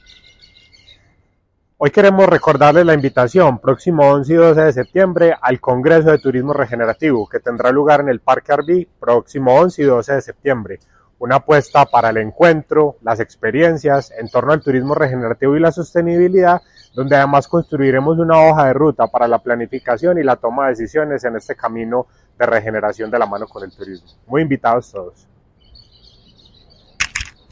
Declaraciones-del-secretario-de-Desarrollo-Economico-de-la-Gobernacion-de-Antioquia-Manuel-Naranjo-Giraldo_mp3.mp3